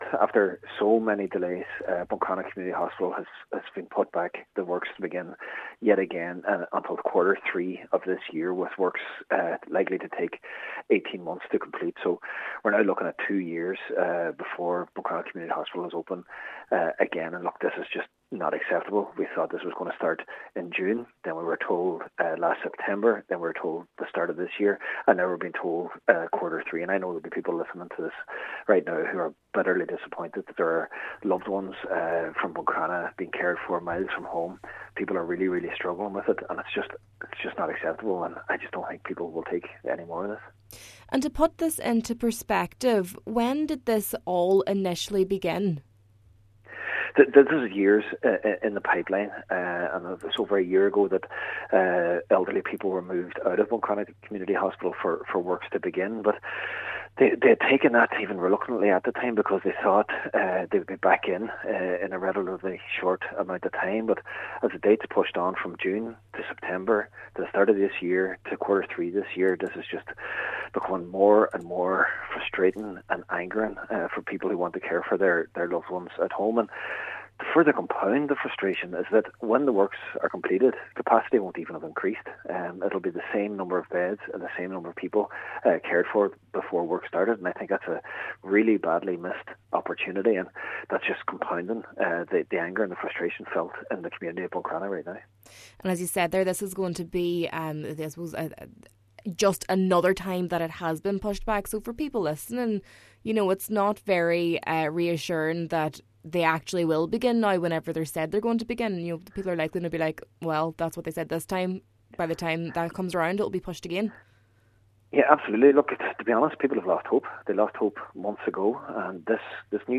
Cllr Jack Murray says it means it will be two years before it can come back into use: